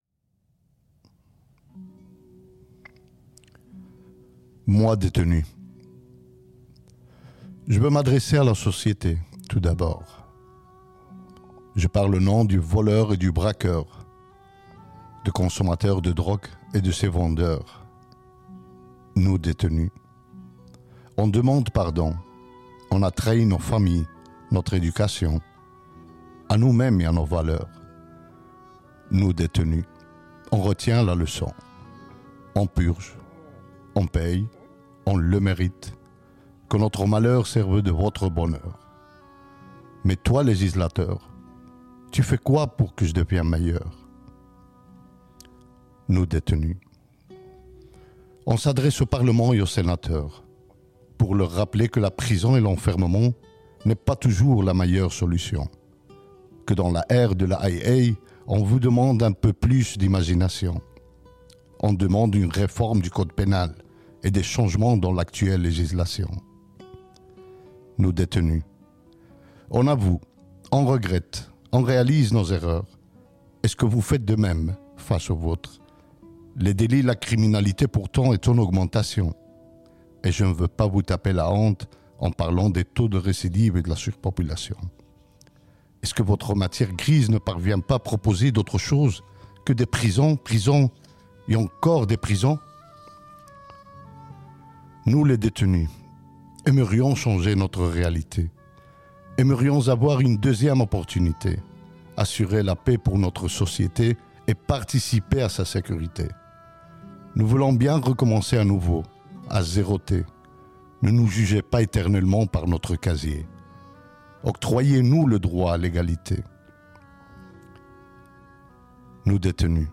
Lu et écrit par le détenu